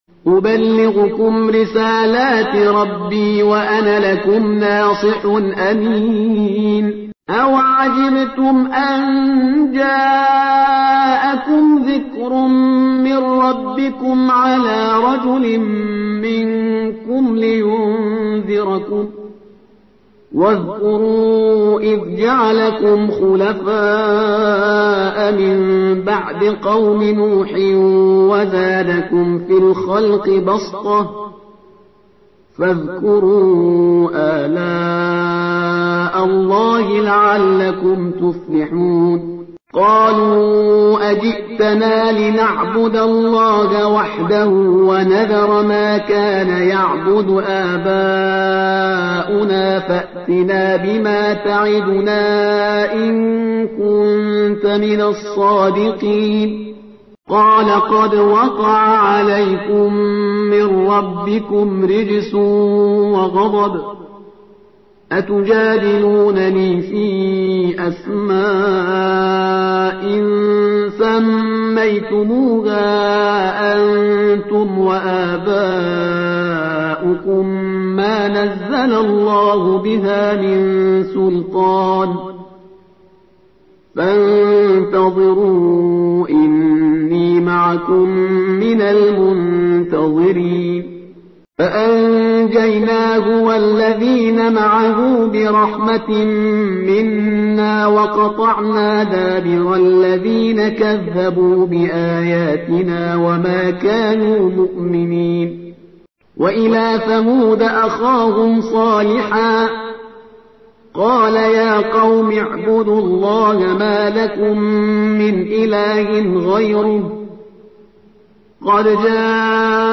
ترتیل صفحه ۱۵۹ سوره سوره اعراف با قرائت استاد پرهیزگار(جزء هشتم)